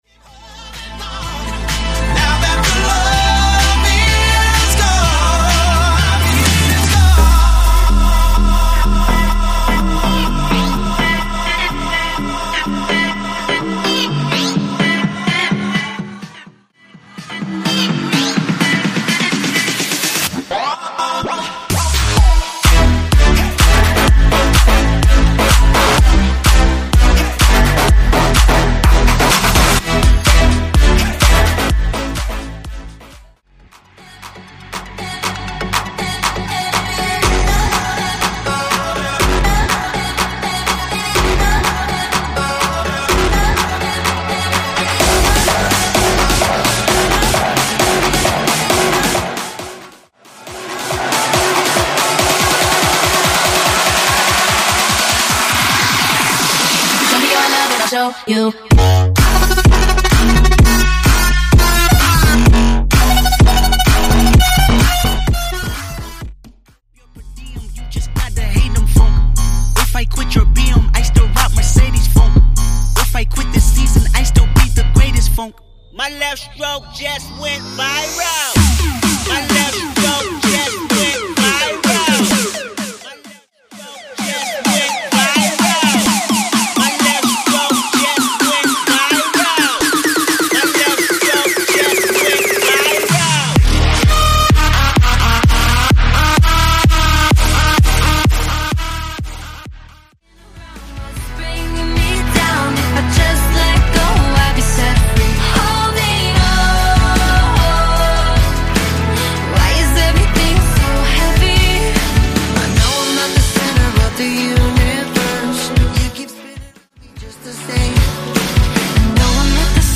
80s Lovesong Redrum - Hiphop Beat
80s Dance Rework
80s Rock Lovesong Redrum
80s New Wave Redrum